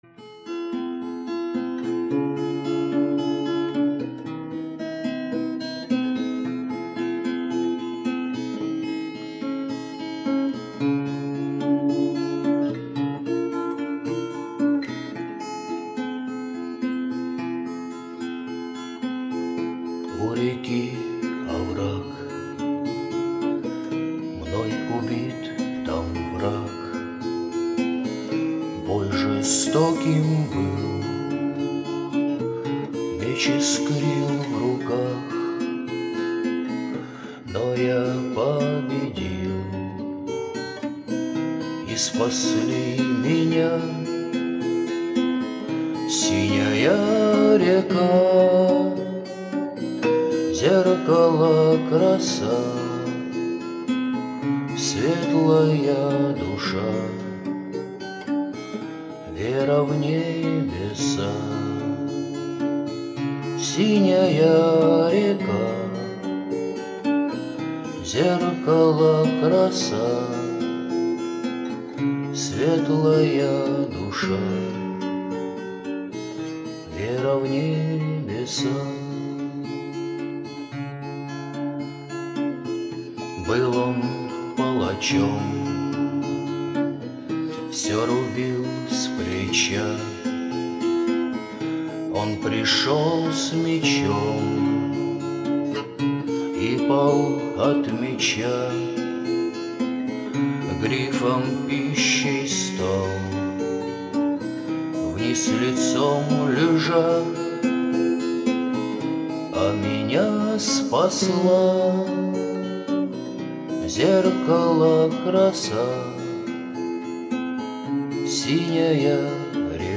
авторская песня в исполнении автора под гитару